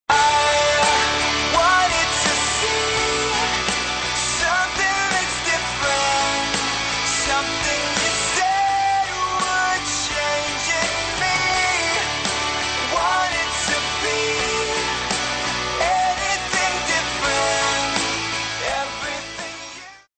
Rap & Hip Hop